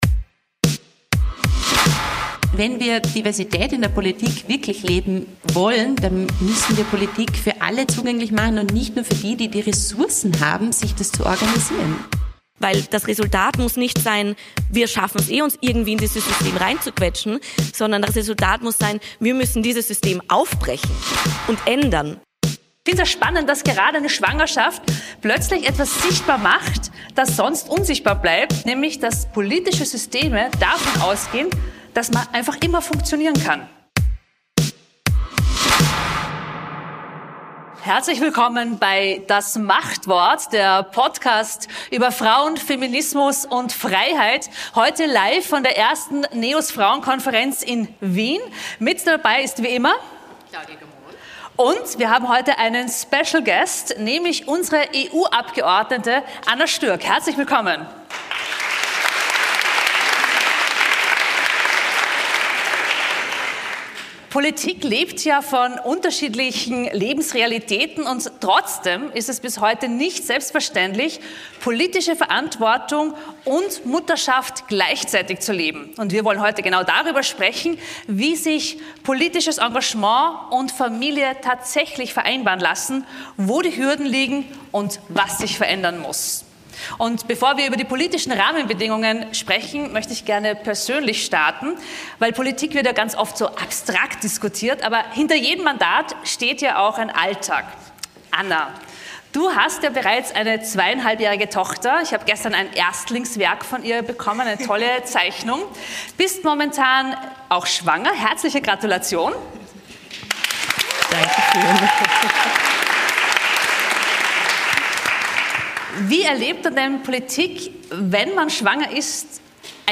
Und was braucht es, damit mehr Frauen – und besonders Mütter – in der Politik vertreten sind? Darüber sprechen Claudia Gamon, Stv. Parteichefin, Anna Stürgkh, EU-Abgeordnete und Frauensprecherin Henrike Brandstötter von den NEOS bei einem Live-Podcast im Rahmen der 1. NEOS Frauen Konferenz.